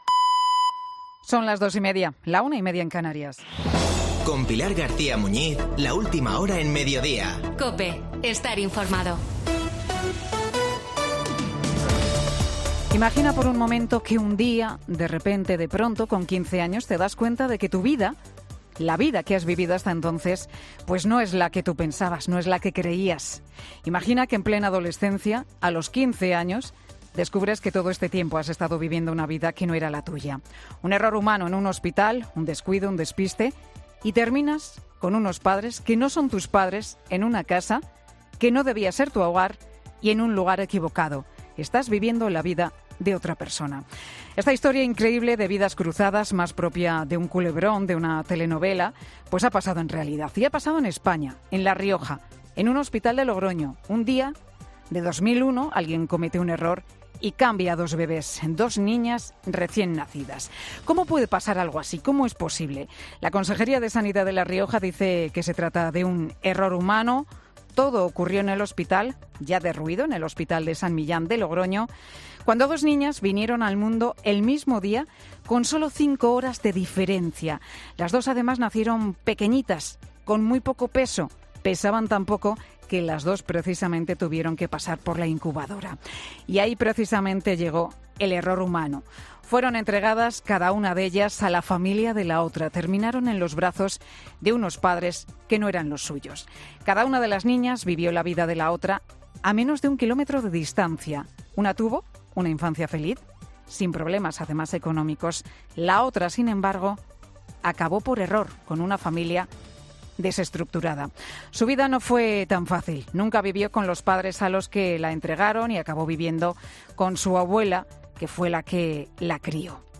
AUDIO: El monólogo de Pilar García Muñiz en Mediodía COPE